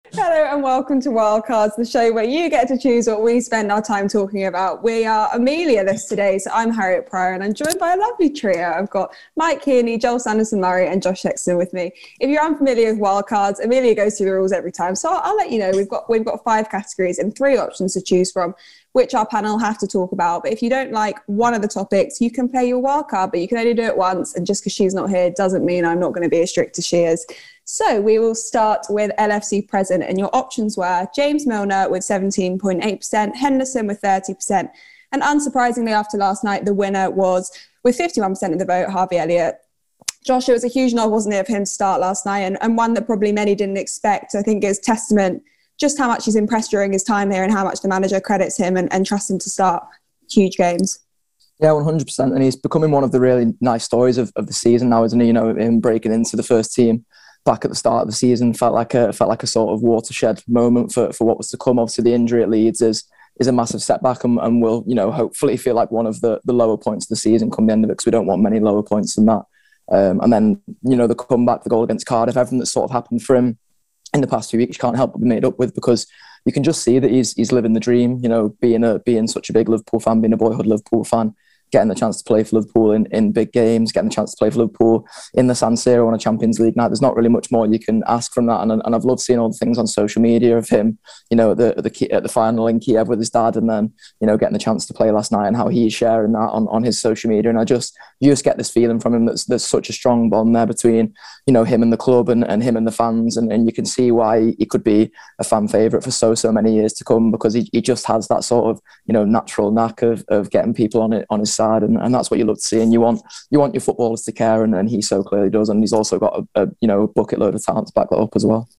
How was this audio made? Below is a clip from the show – subscribe for more on Harvey Elliott…